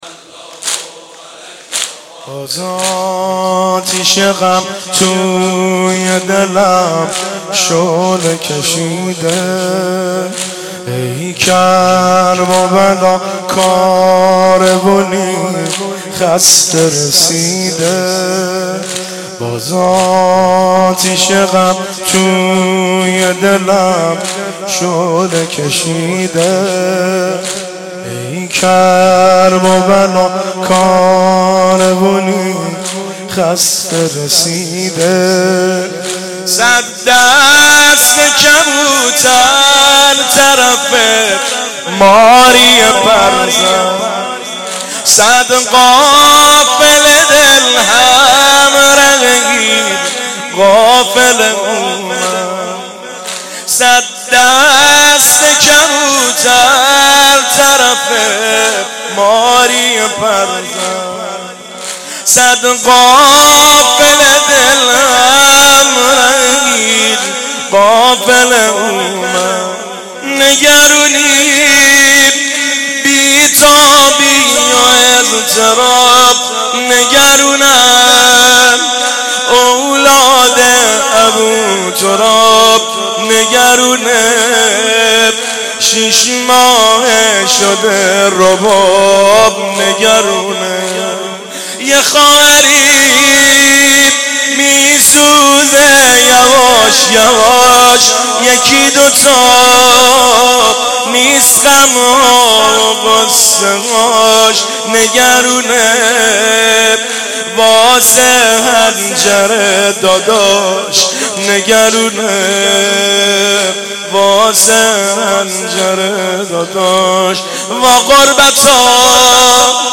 شب دوم محرم الحرام 95/ هیئت غریب مدینه امیر کلا